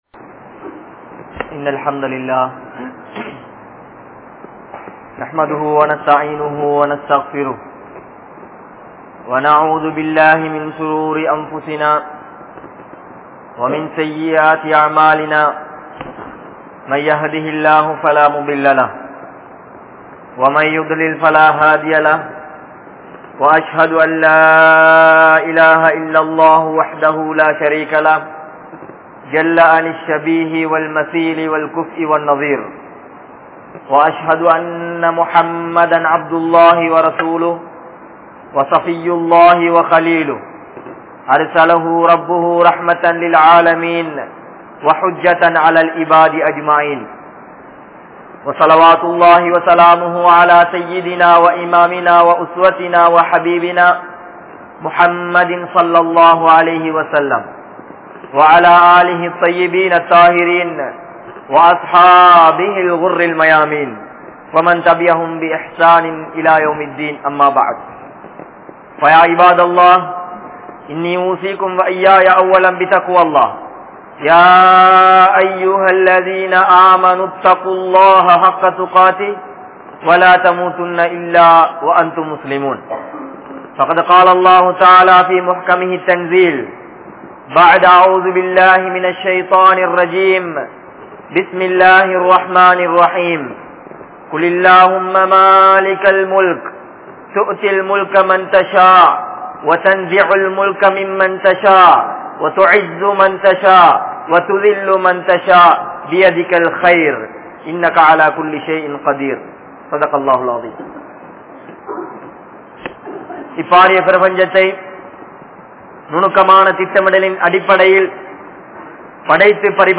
Anniyavarhalukku Vaakkalikka Mudima?? (அன்னியவர்களுக்கு வாக்களிக்க முடியுமா??) | Audio Bayans | All Ceylon Muslim Youth Community | Addalaichenai
Hudha Jumua Masjidh